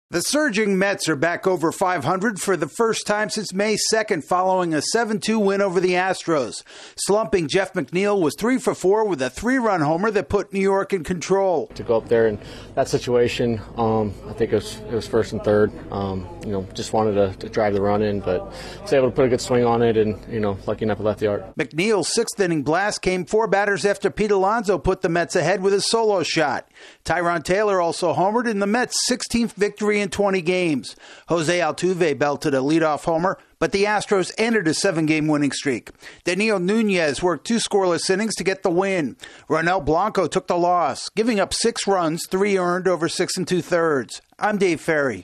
The Mets win a battle of hot teams. AP correspondent